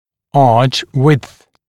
[ɑːʧ wɪdθ][а:ч уидс]ширина зубной дуги